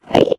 Sound / Minecraft / mob / endermen / idle1.ogg